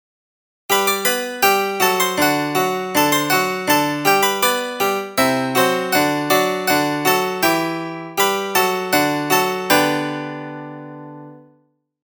the-blackbirds-response-harpsichord.mp3